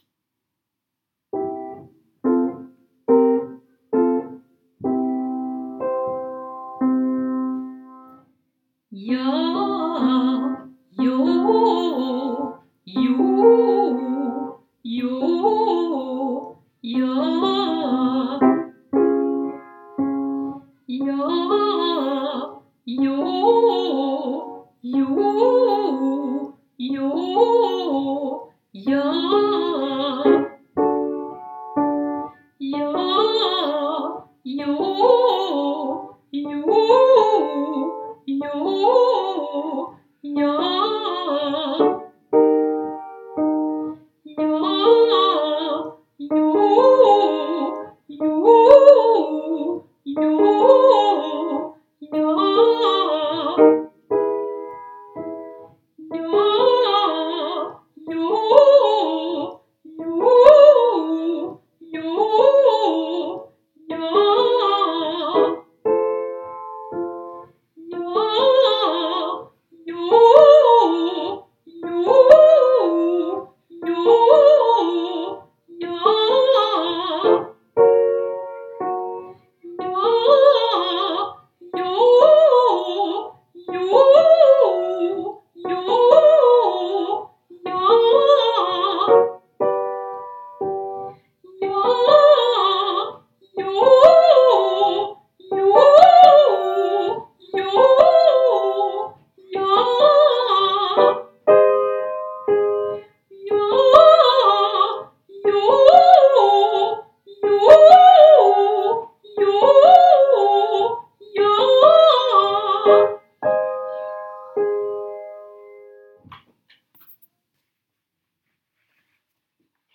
Übungen für Fortgeschrittene
2_Ja,+Jo,+Ju+mit+Septime_ad.m4a